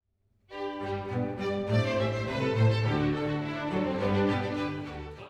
The pace is brisk, the mood is jolly, and hardly a word is repeated.
The singing is mostly Type I, but with brief imitative passages.
01-Violin-chirping.wav